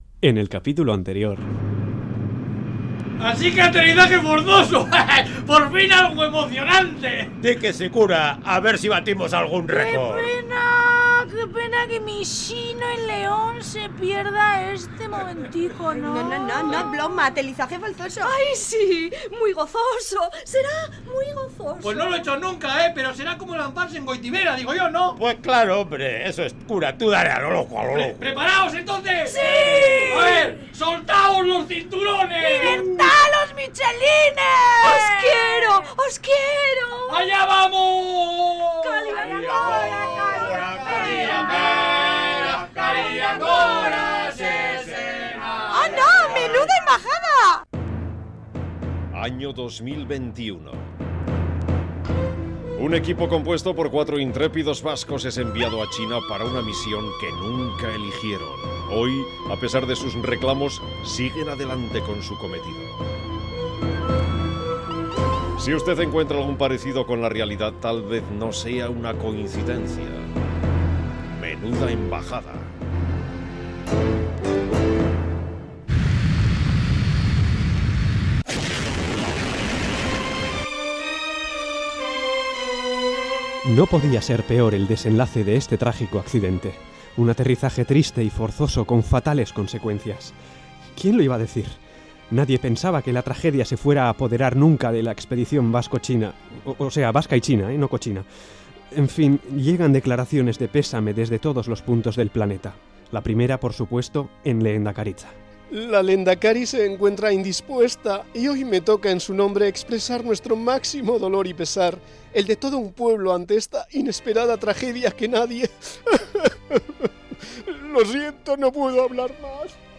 Radio Euskadi RADIONOVELA Menuda Embajada capítulo 9 Última actualización